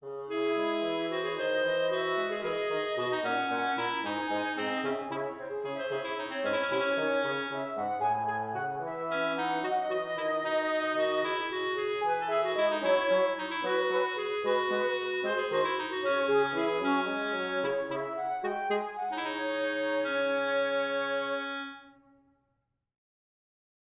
Chamber